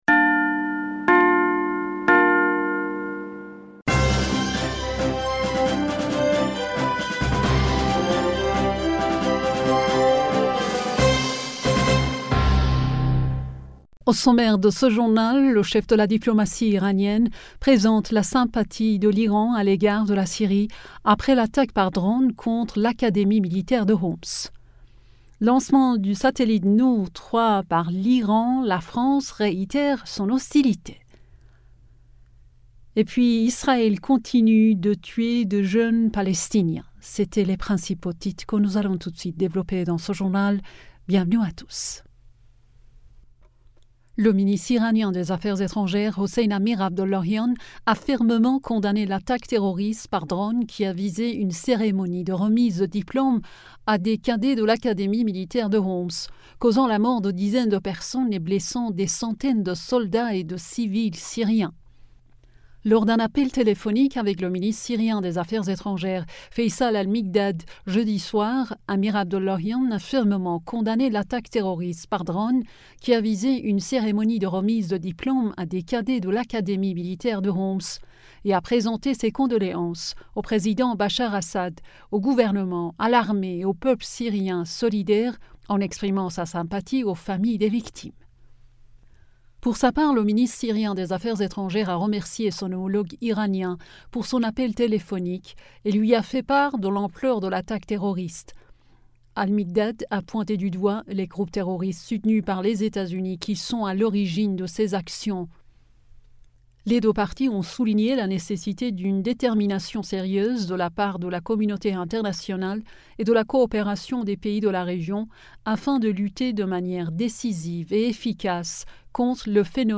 Bulletin d'information du 06 Octobre 2023